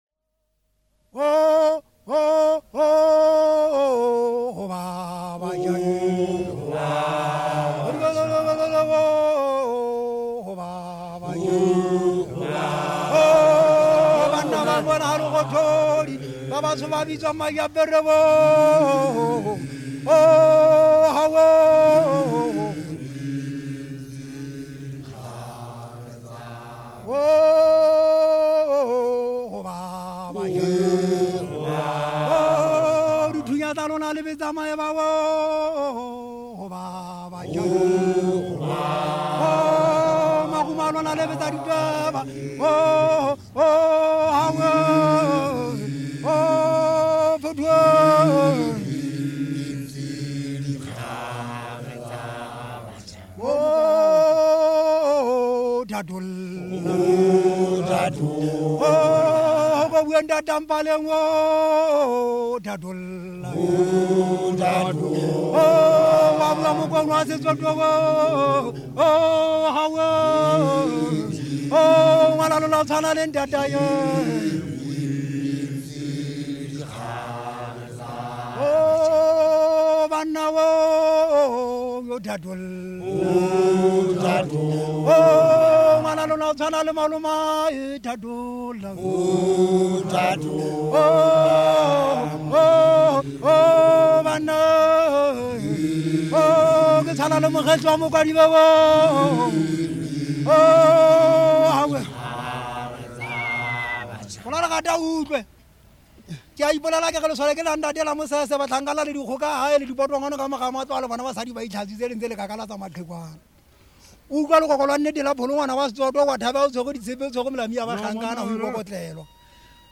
Folk songs, Sotho
Folk music
Field recordings
Africa Lesotho Maseru f-lo
sound recording-musical
Mokorotlo riding song.